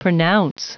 Prononciation du mot pronounce en anglais (fichier audio)
pronounce.wav